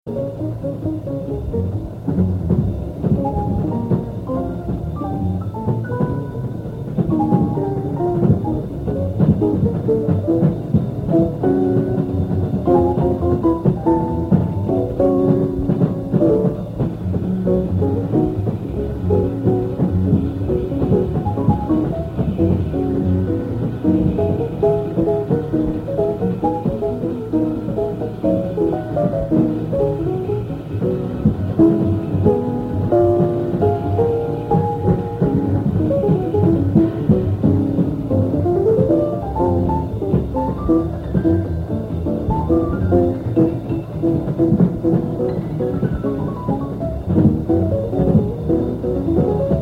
un enregistrement en club de 1957